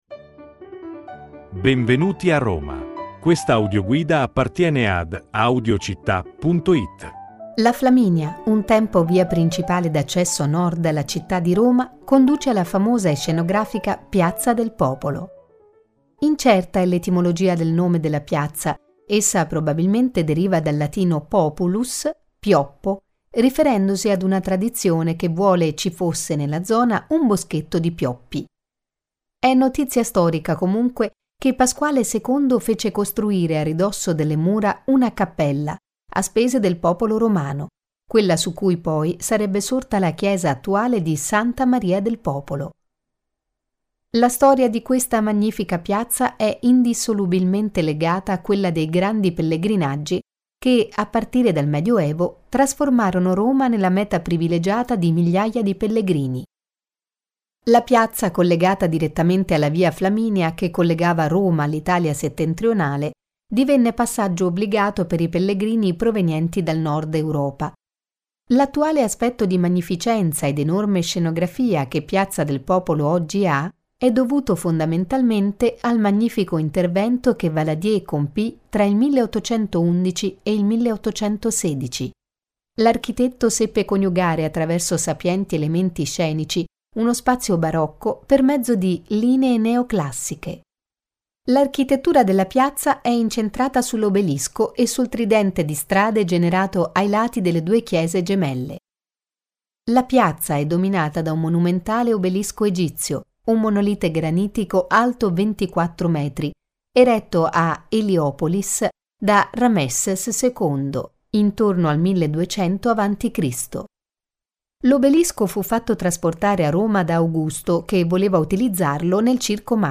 Audioguida Roma – Piazza del Popolo